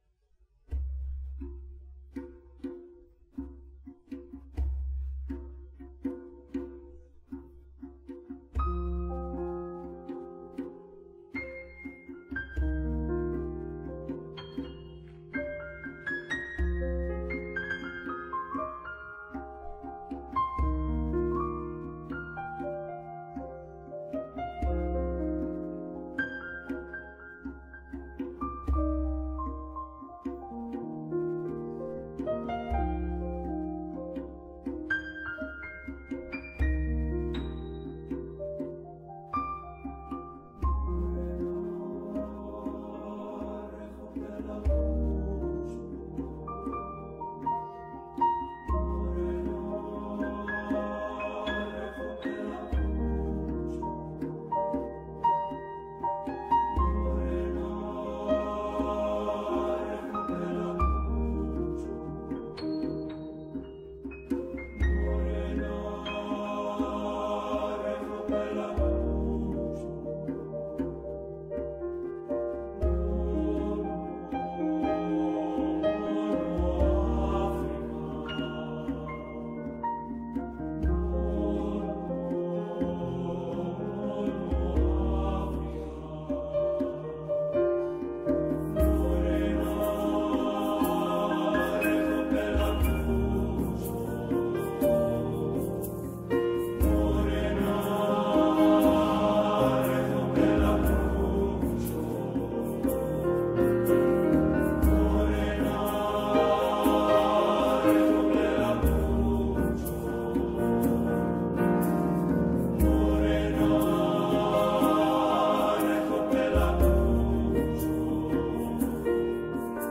Voicing: "SATB divisi"